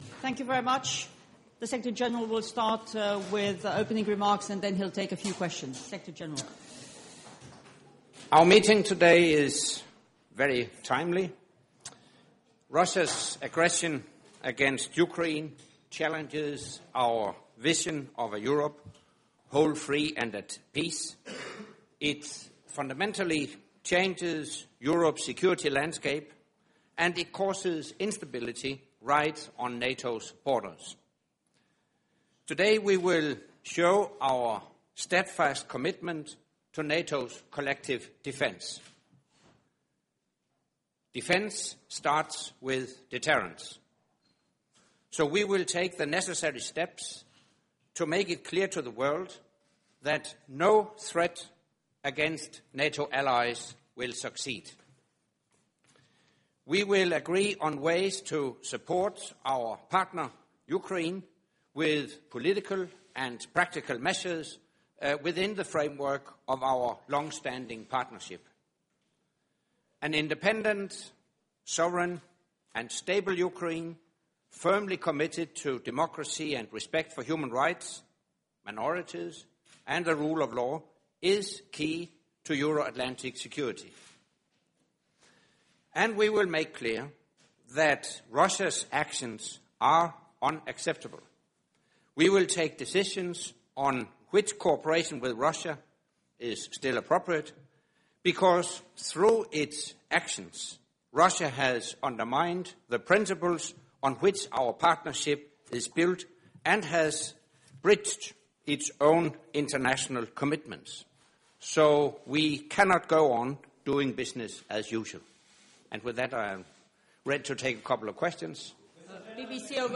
Doorstep statement by NATO Secretary General Anders Fogh Rasmussen at the start of the NATO Foreign Affairs Ministers meetings